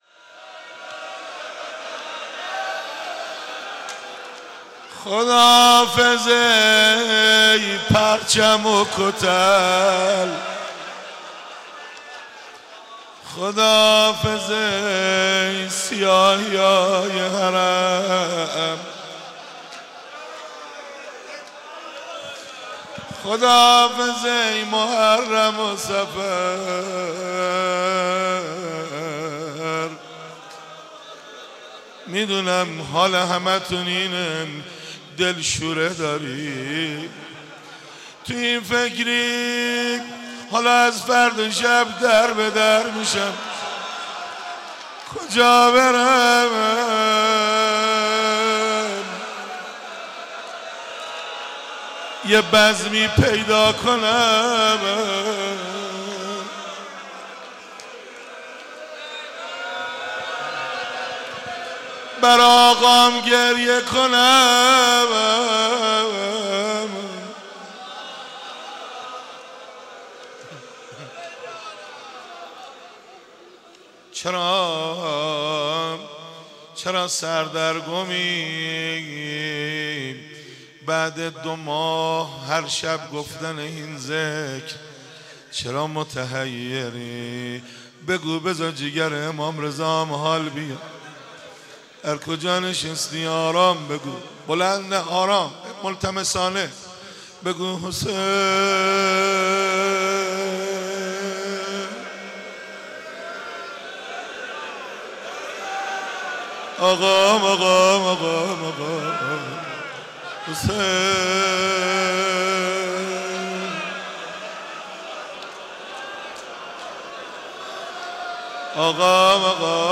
آخرین روضه ماه صفر
در مسجد حضرت امیر